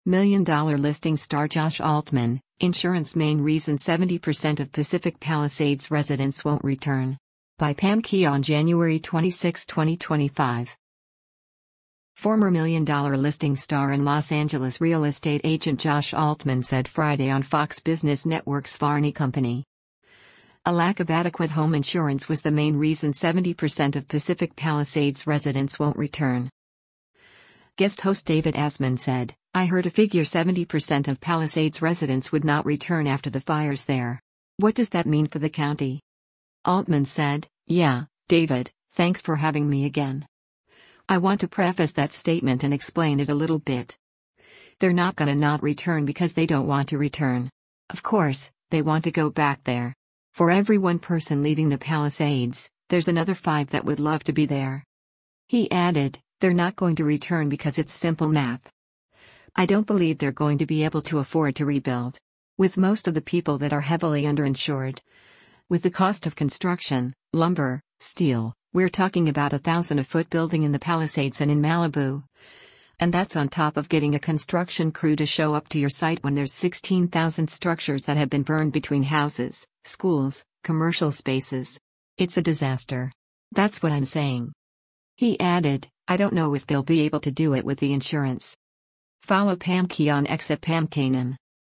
Former ‘Million Dollar Listing’ star and Los Angeles real estate agent Josh Altman said Friday on Fox Business Network’s “Varney Co.” a lack of adequate home insurance was the main reason 70% of Pacific Palisades residents won’t return.
Guest host David Asman said, “I heard a figure 70% of Palisades residents would not return after the fires there. What does that mean for the county?”